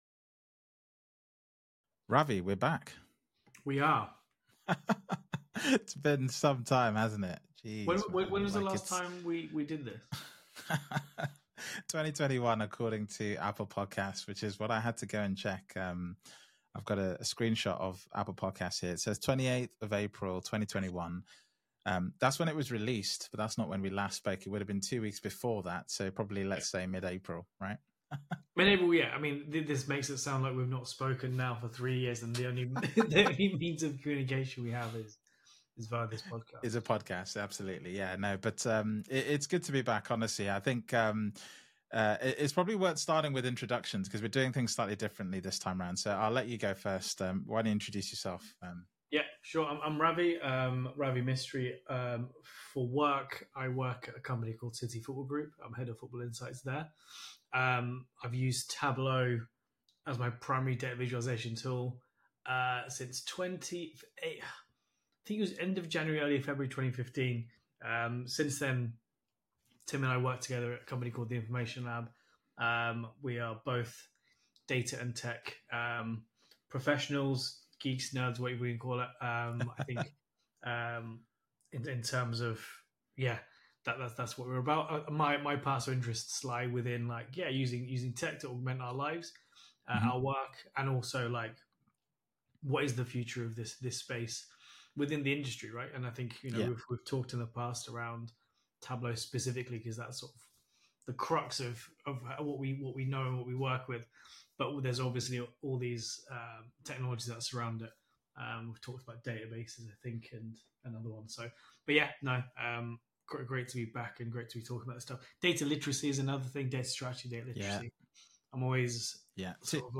Play Rate Listened List Bookmark Get this podcast via API From The Podcast 2 data professionals discuss data and technology. Bits focus on technologies, Bytes focus on high-level concepts we come across.